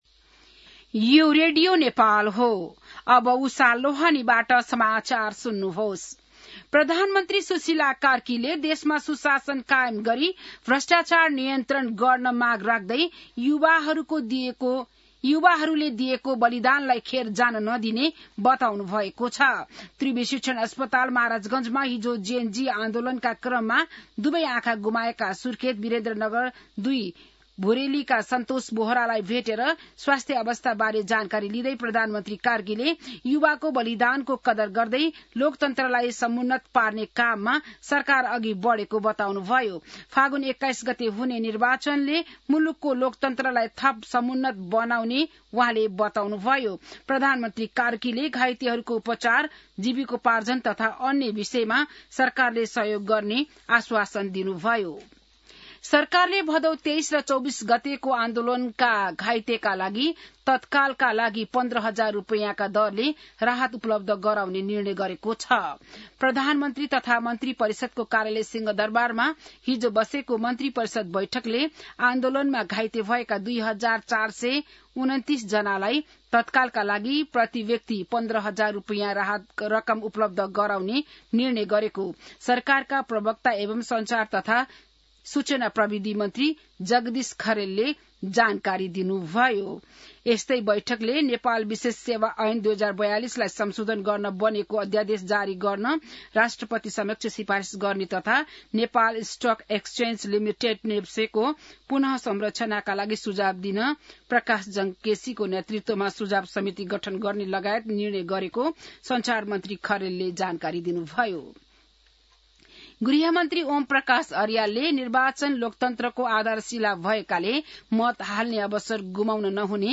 बिहान १० बजेको नेपाली समाचार : ३ मंसिर , २०८२